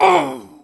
55 KB sharp pain Category:Soldier audio responses
Soldier_painsharp08.wav